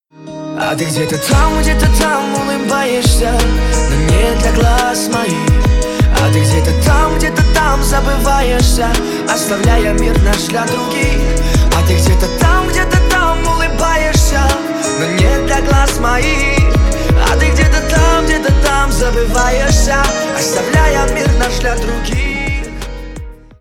Рингтоны на звонок
Нарезка припева на вызов